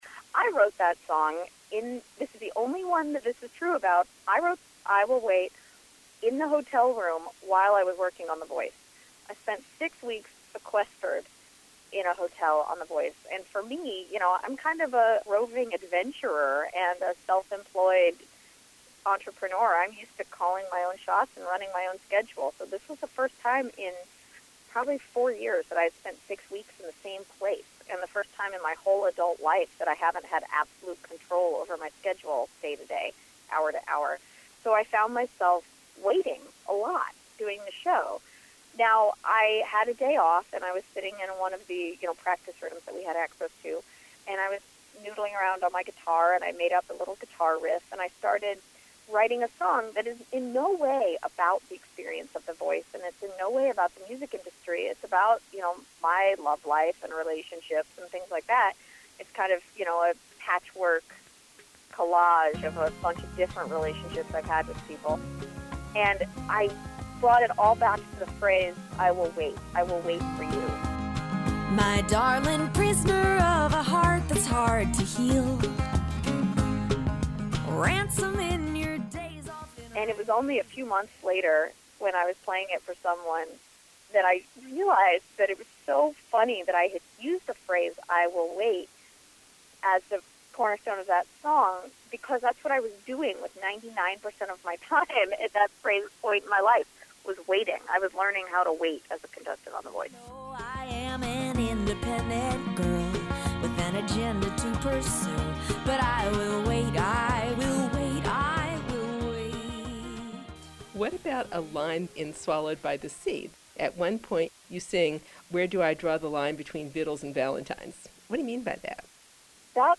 Interview, Part 2